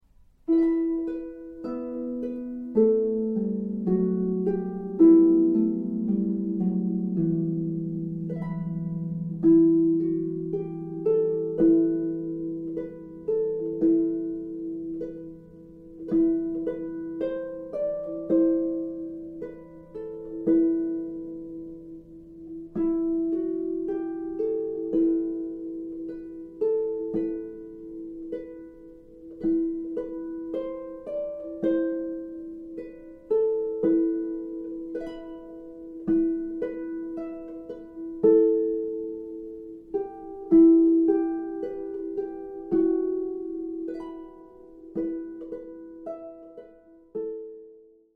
solo harp demo files
Celtic harp